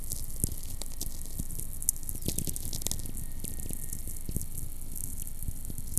Heidelberg, Germany